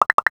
NOTIFICATION_Pop_02_mono.wav